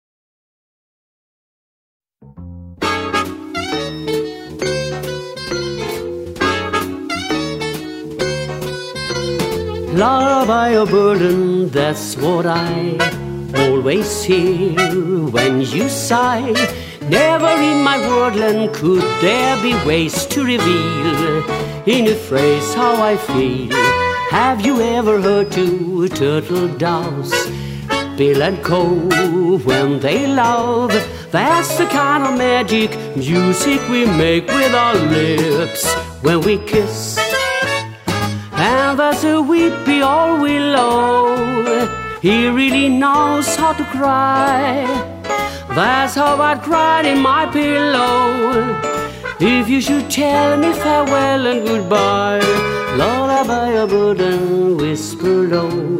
Balladen,  Jazz und Swing
mit ihrer tiefen Kontra-Alt Stimme
Orchesterbegleitung: Combo oder Big Band